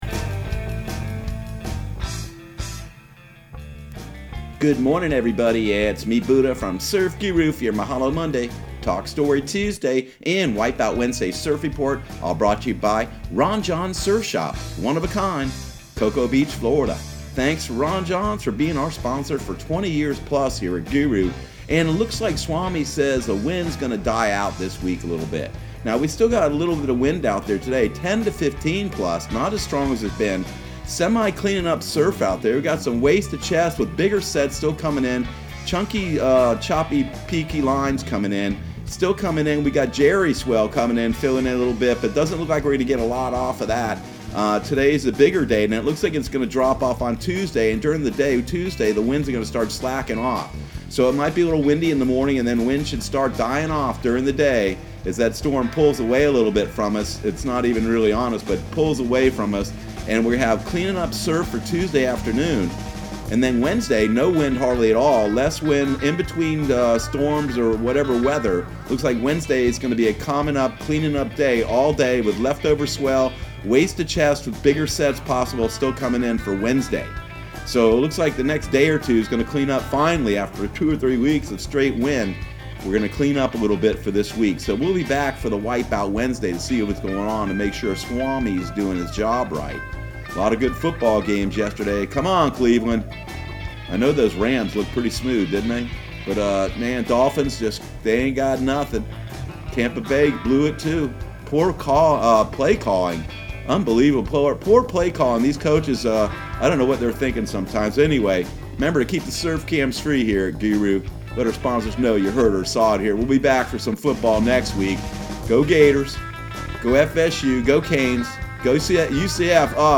Surf Guru Surf Report and Forecast 09/23/2019 Audio surf report and surf forecast on September 23 for Central Florida and the Southeast.